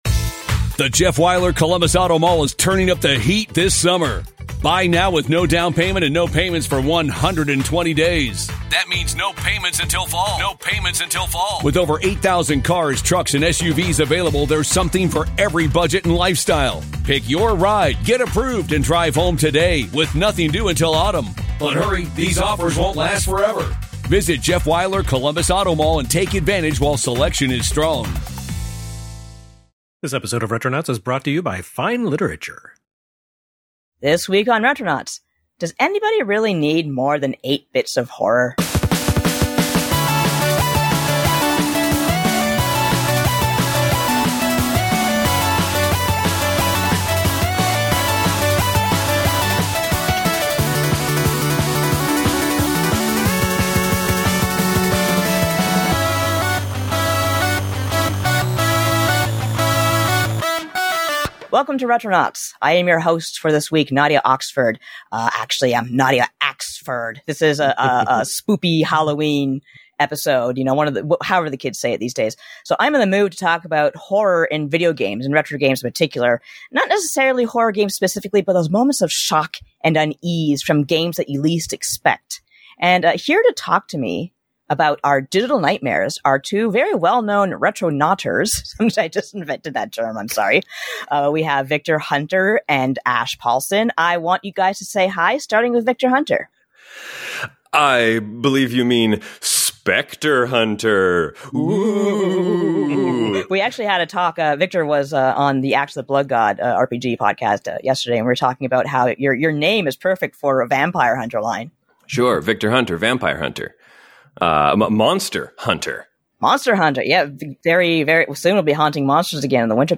talk about retro games outside the horror genre that scared them